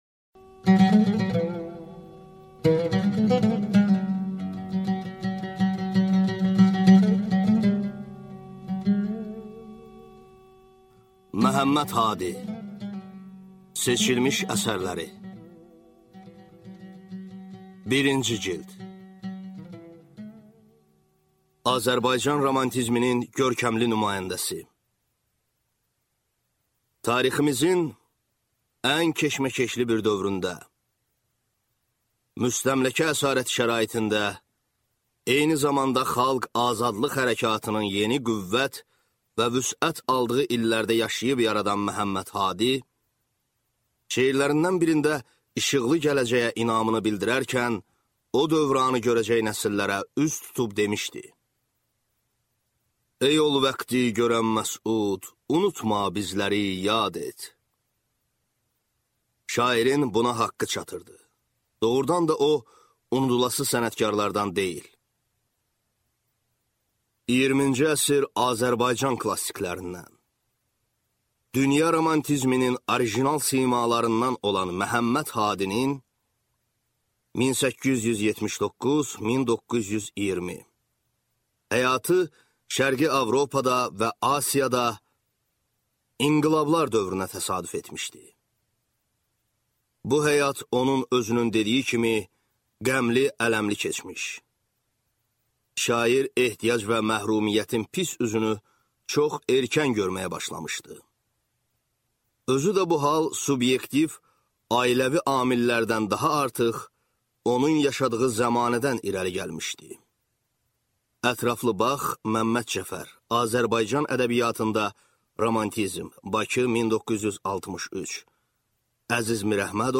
Аудиокнига Məhəmməd Hadinin seçilmiş əsərləri | Библиотека аудиокниг